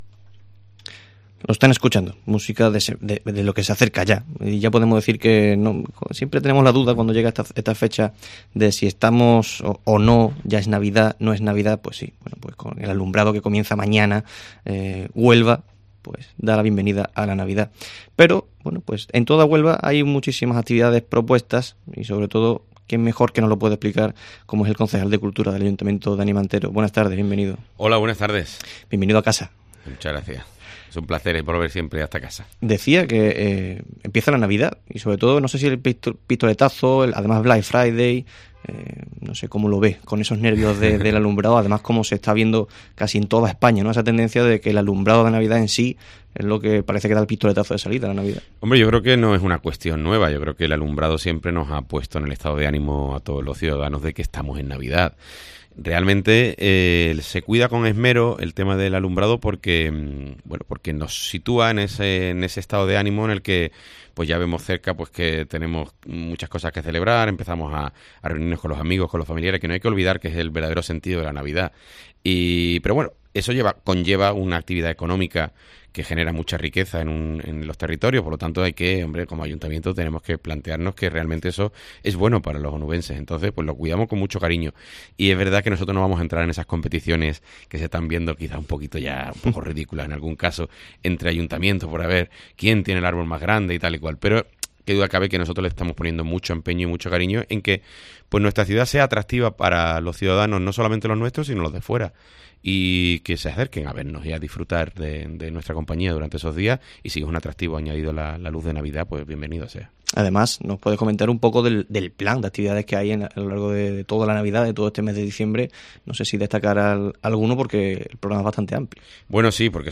AUDIO: Daniel Mantero, concejal de Cultura del Ayuntamiento de Huelva, desgrana la programación de Navidad y el alumbrado que se inaugurará este 29...